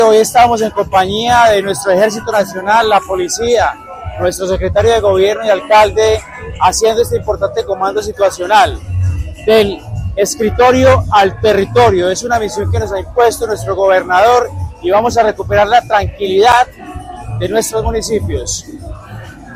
Audio de: Secretario del Interior Encargado – Diego Alexander Santamaria.
DIEGO_SANTAMARIA_OPERATIVO_LATEBAIDA.mp3